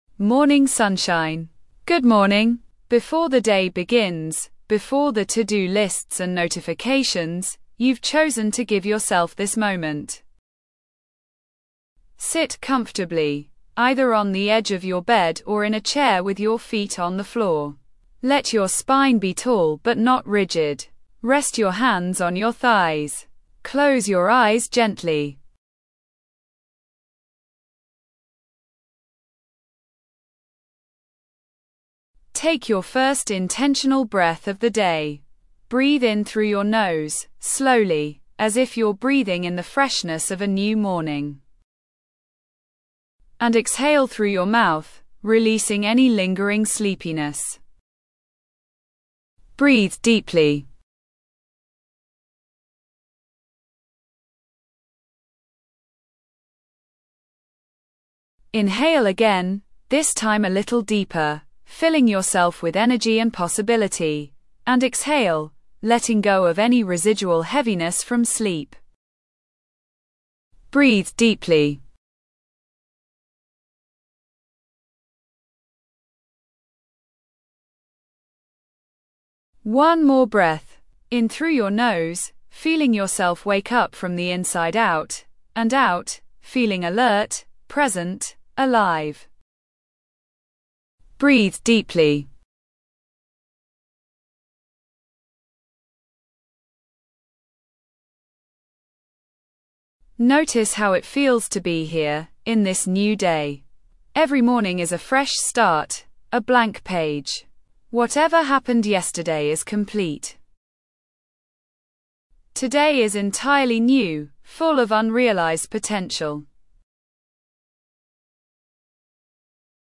Guided Morning Meditation: Start Your Day with Energy & Clarity | Positivity